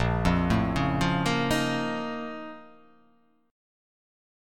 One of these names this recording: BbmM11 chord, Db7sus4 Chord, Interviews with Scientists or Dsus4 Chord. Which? BbmM11 chord